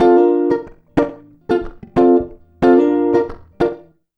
92FUNKY  4.wav